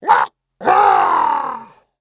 monster2 / gold_monkey / dead_1.wav